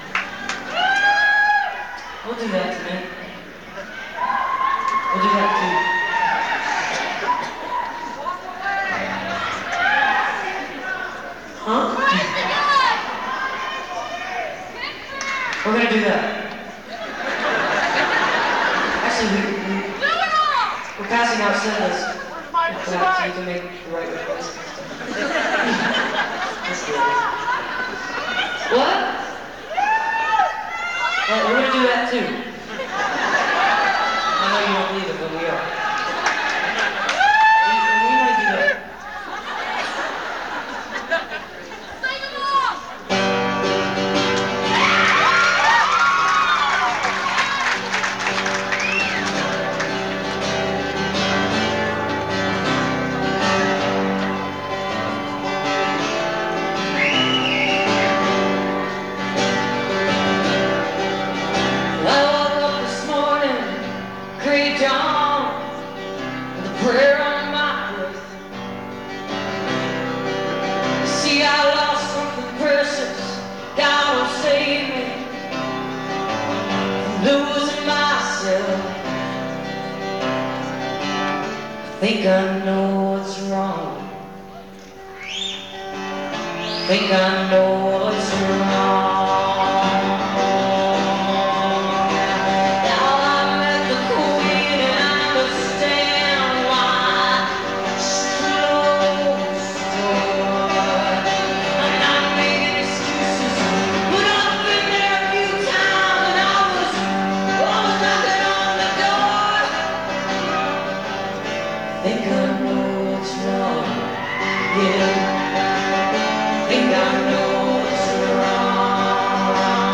acoustic duo